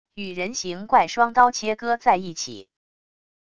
与人形怪双刀切割在一起wav音频